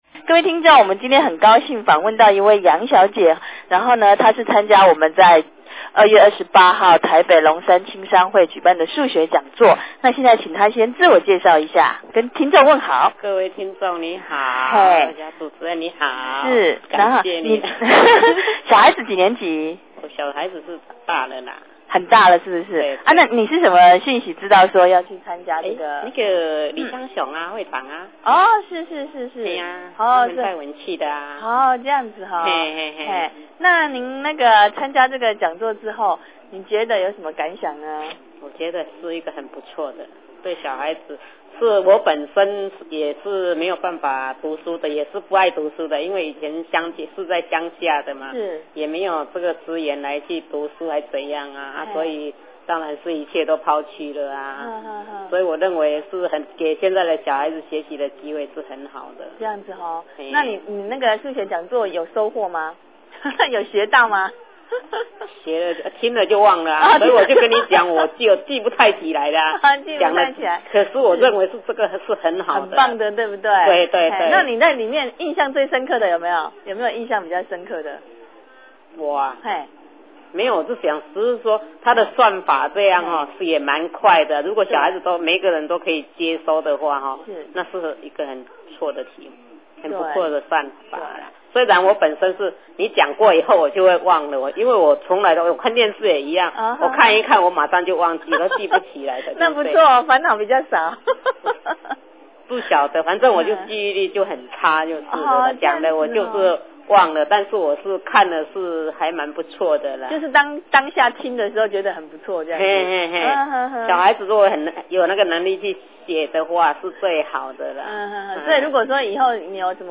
聲音)：９８年２月２８日台北市龍山青商會數學講座。